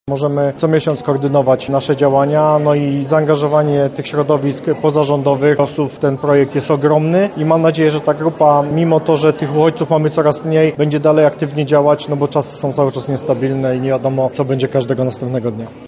-podkreślił wicewojewoda lubelski, Andrzej Maj.